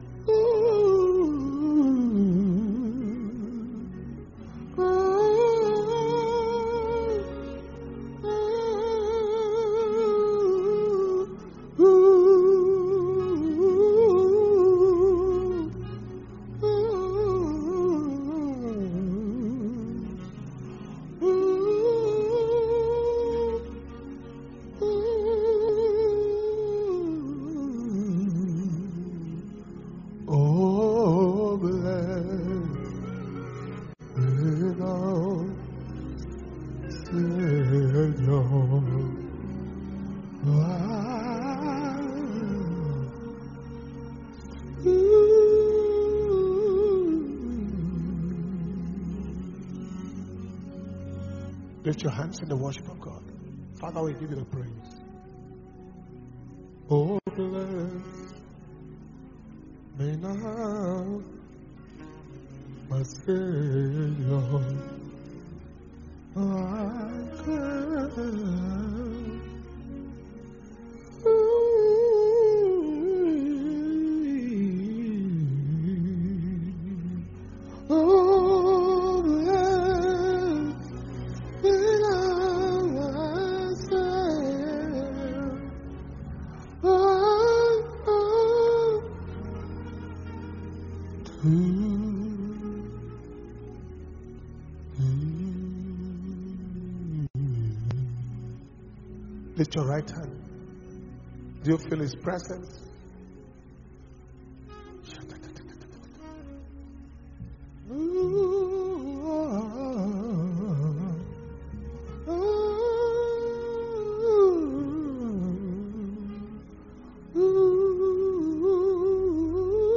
Power Communion Service – Wednesday, 13th October 2021